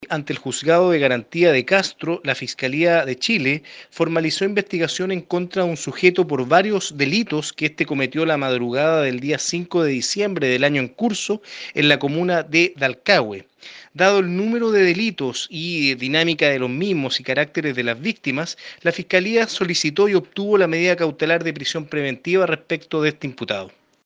Información que fue proporcionada por el fiscal del ministerio Público, Cristian Mena.
09-FISCAL-CRISTIAN-MENA-POR-ROBO.mp3